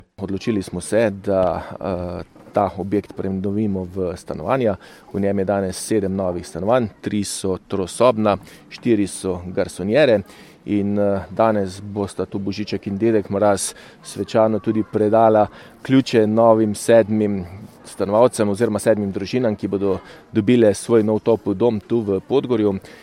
Dela so se začela konec leta 2022, včeraj pa so jih predali novim stanovalcem. Več pa župan Tilen Klugler.